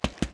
rogue_skill_dash_attack_loop.wav